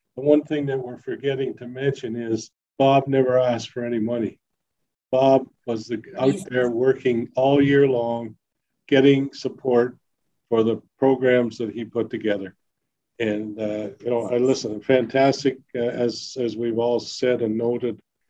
Before the committee voted unanimously in favour of the recommendation some members sang the praises of the former councillor.
Mayor Jim Harrison echoed the praise.